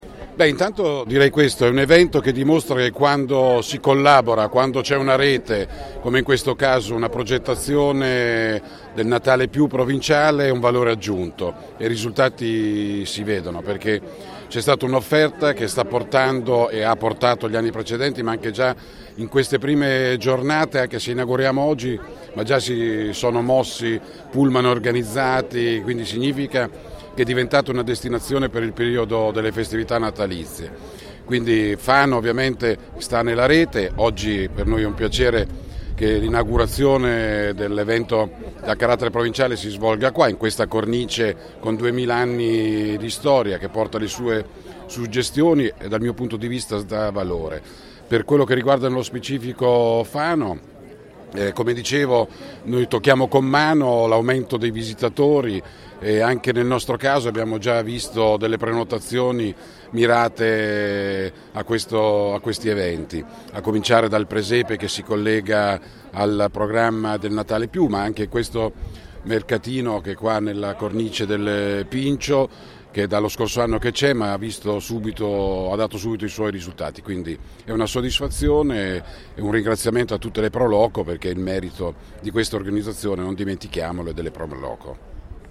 Ce ne parla ai nostri microfoni il Sindaco, di Fano Massimo Seri.